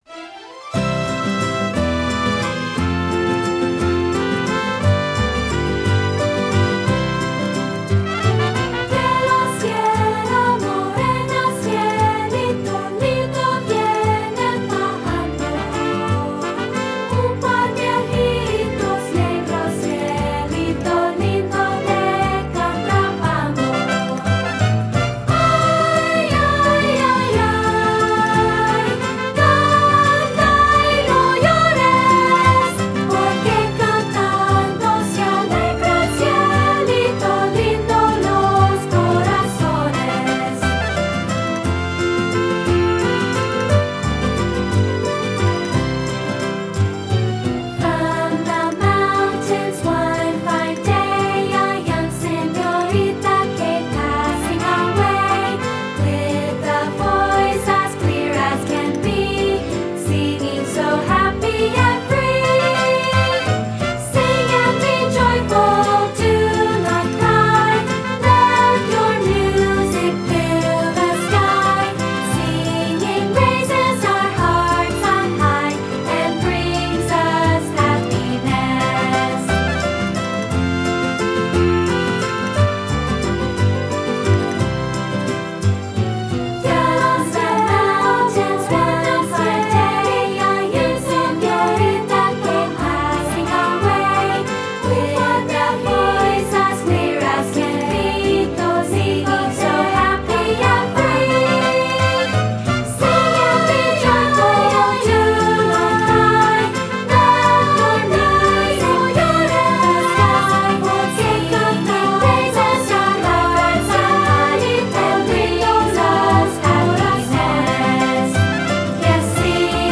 General Music Elementary Choral & Vocal Multicultural Choral
Mexican Folk Song